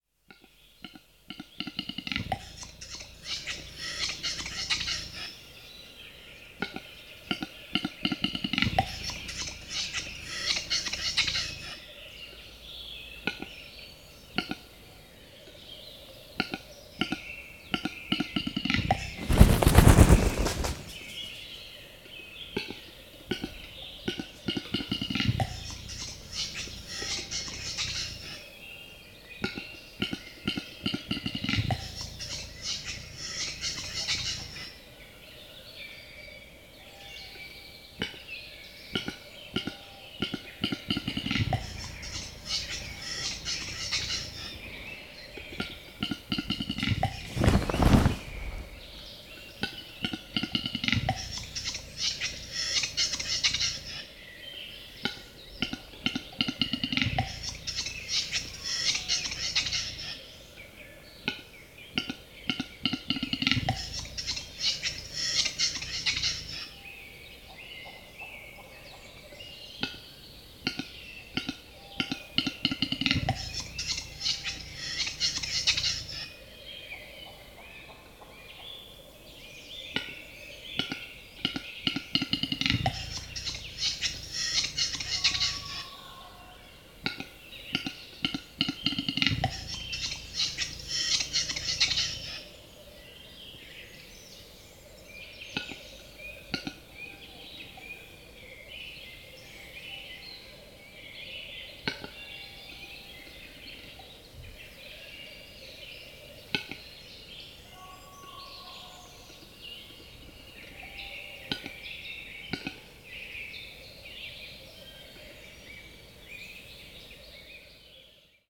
Auerhahn Geräusch
• Bei der Balz macht er ein markantes Klick-Klacks-Geräusch, das bis zu 1 km weit hörbar ist.
Sein Balzgesang ist ein knackendes, zischendes Geräusch, das oft mit einem Korkenknall endet.
Auerhahn-Geraeusch-Voegel-in-Europa.wav